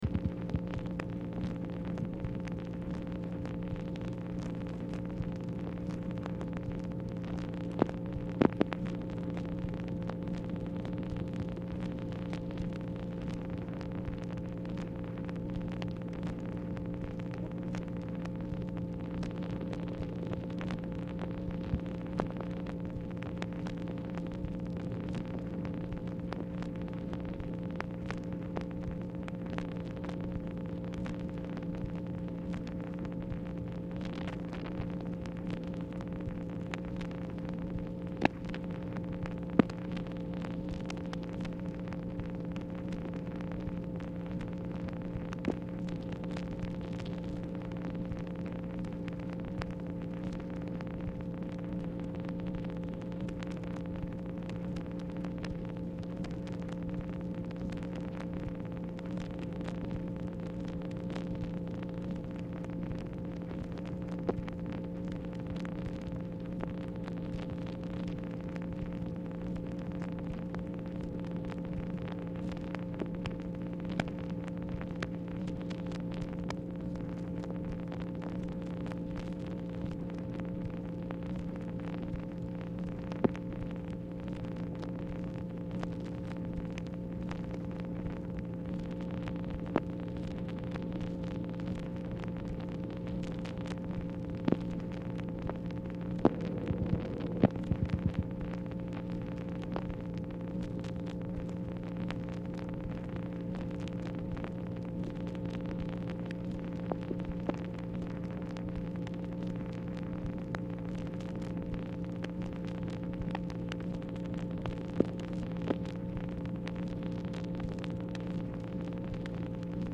Telephone conversation # 4861, sound recording, MACHINE NOISE, 8/10/1964, time unknown
MACHINE NOISE
Oval Office or unknown location
Telephone conversation
Dictation belt